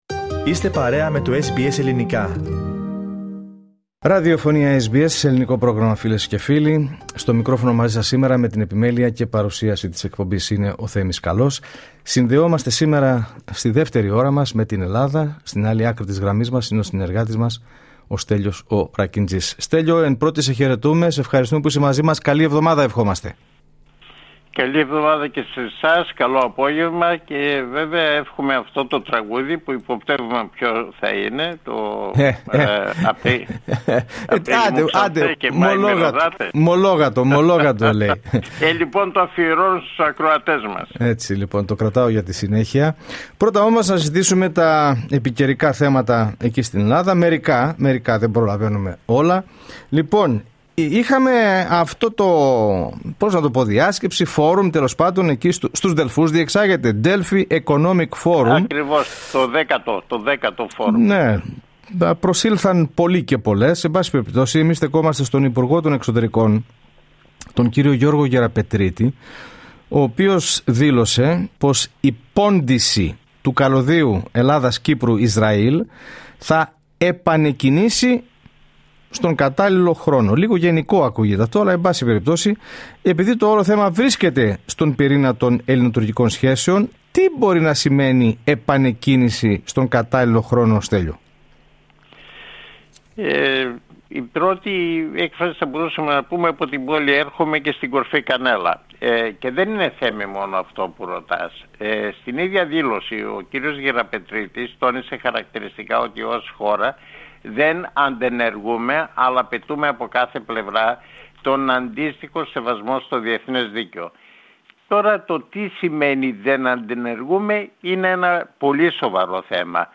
Ακούστε την εβδομαδιαία ανταπόκριση από Ελλάδα